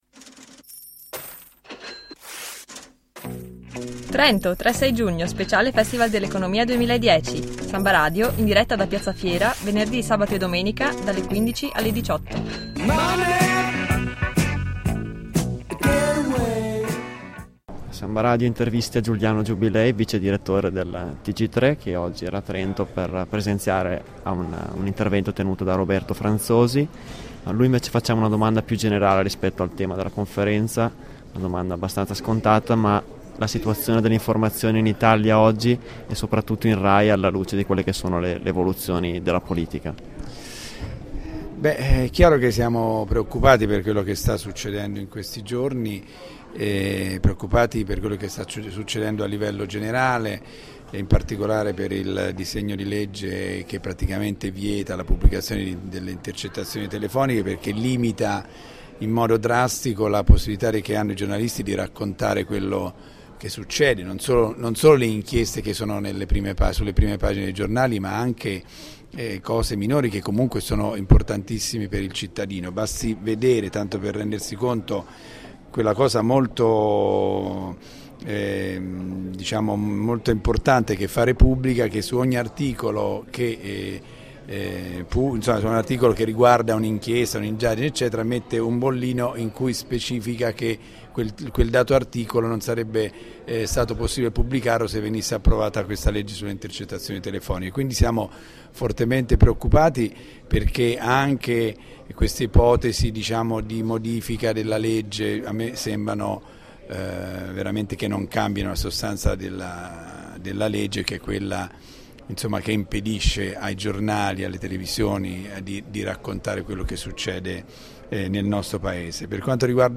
speciali-dal-festival-delleconomia-2010-3-intervista-giuliano-giubilei.mp3